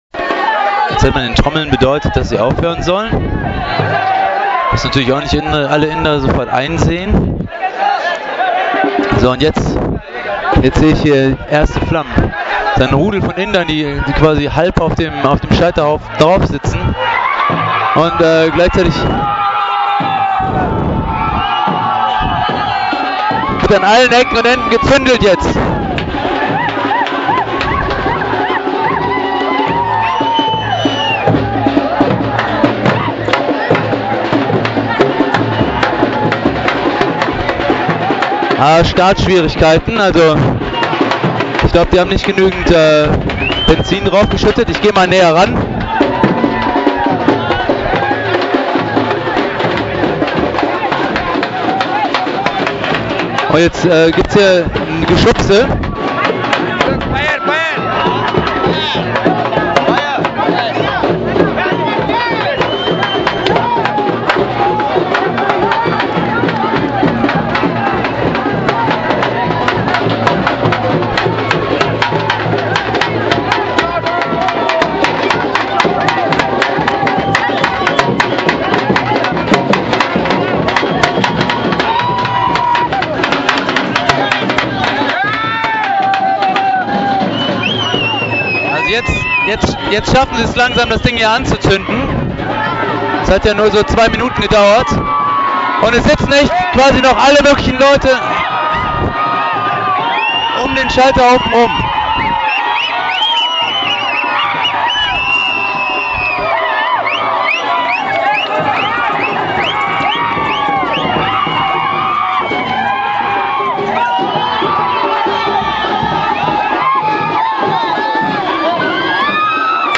Alle Trommeln der Stadt werden zusammengesucht und eine laute und ekstatisch tanzende Prozession zieht einmal durch die Stadt zu einem riesigen aufgeschichteten Scheiterhaufen.